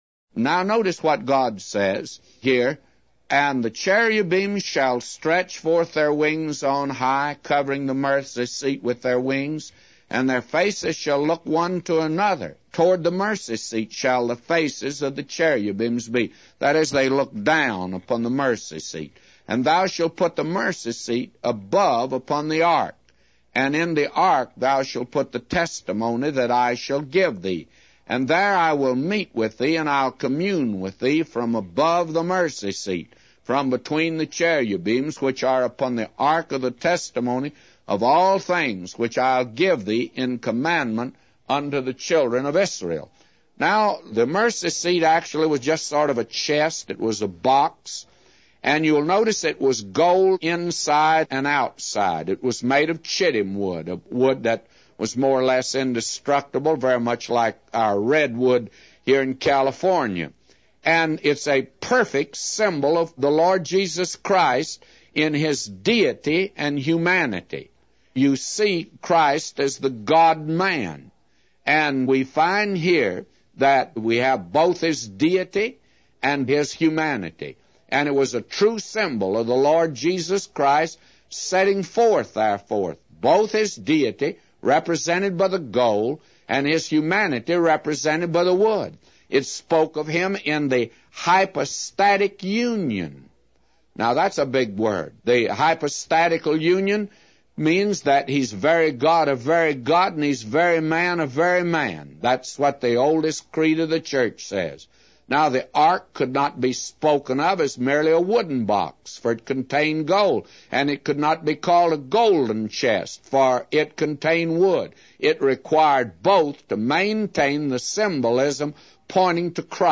A Commentary By J Vernon MCgee For Exodus 25:20-999